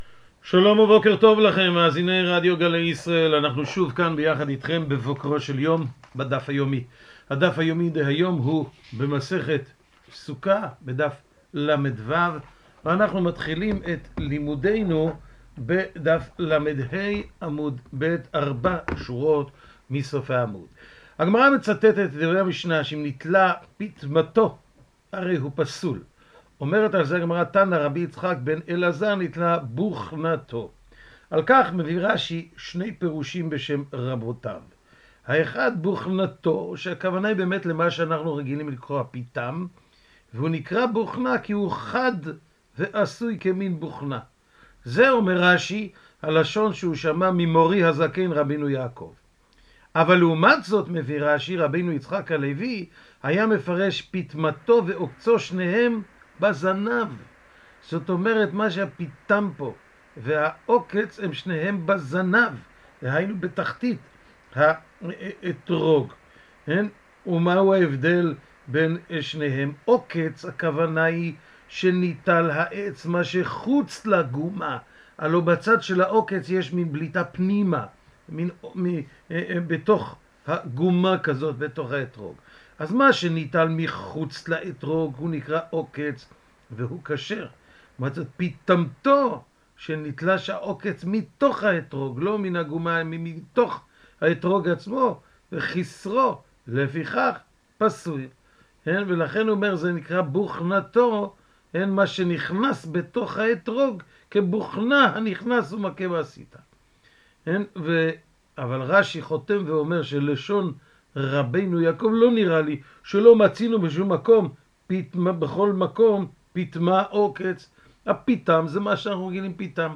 השיעור משודר בשעה 05:30 בבוקר ברדיו גלי ישראל וכל היום באתר סרוגים.